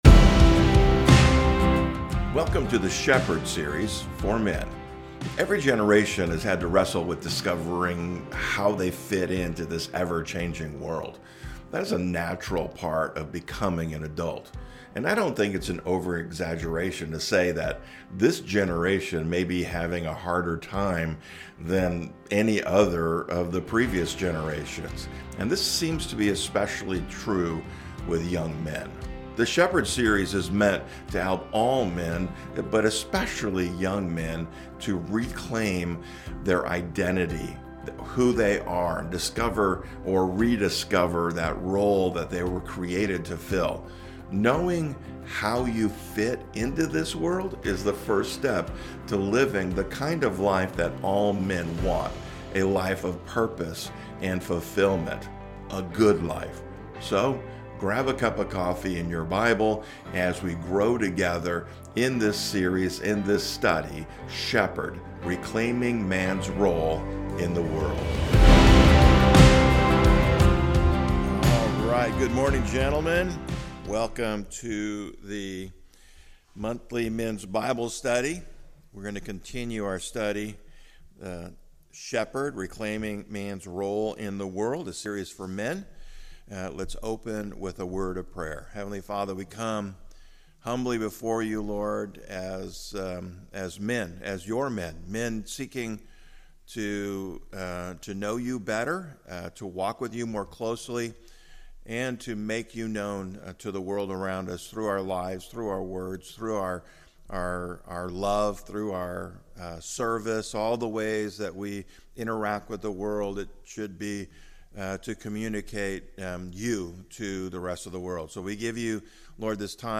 SHEPHERD is a series of messages for men.